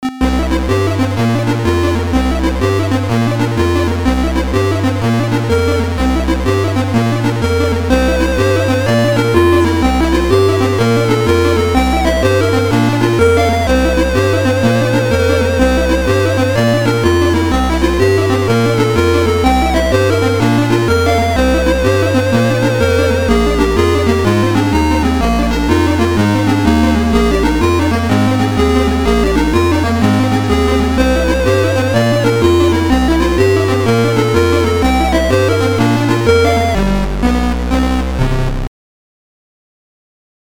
Title the magic spoon Type AHX v2 Tracker